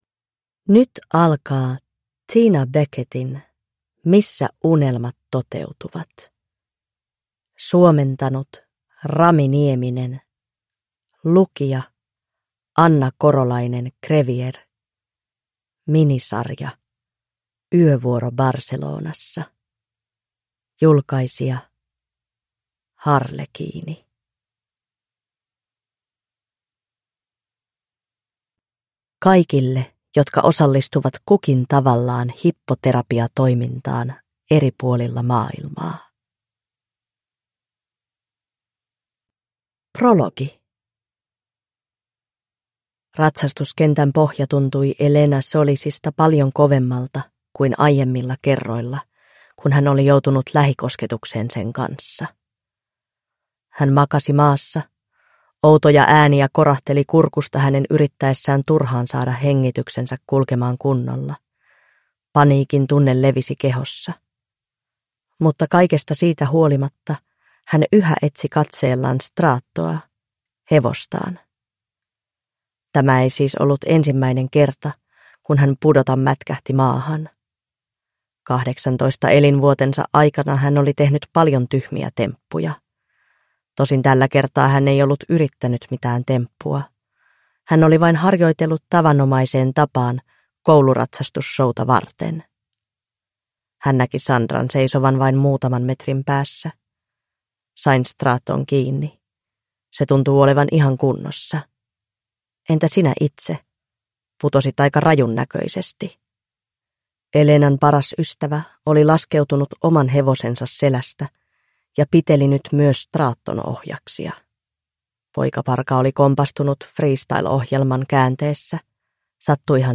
Missä unelmat toteutuvat (ljudbok) av Tina Beckett